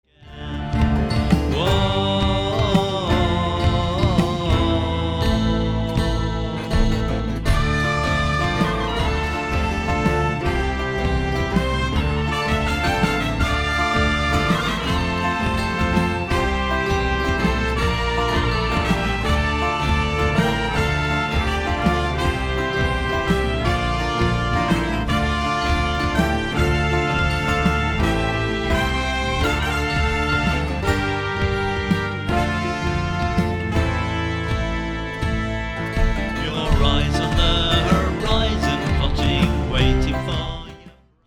mariachi-style trumpets
trumpets
bouzouki